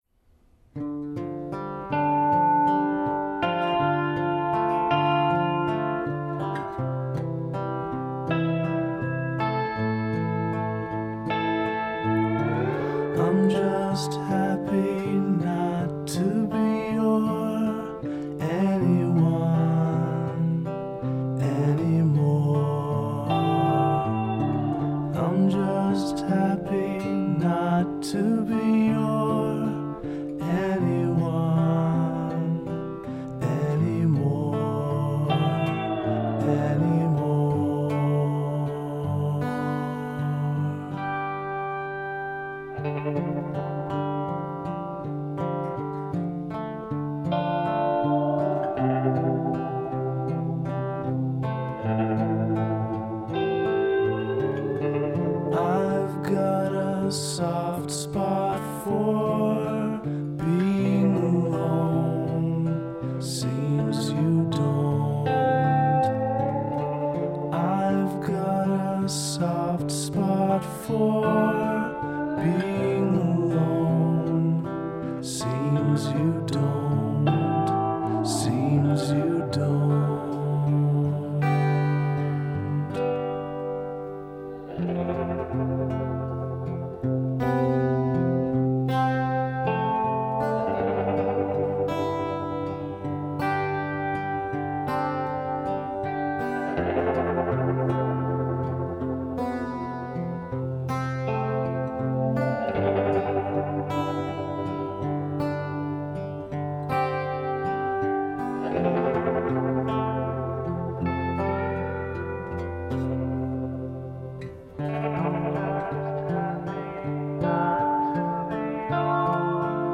just got a starter lapsteel this week.  unfortunately, it’s a bit harder than it looks.  i have a hard enough time singing in tune and now i’ve added an instrument to my collection that requires an ear for pitch.  great…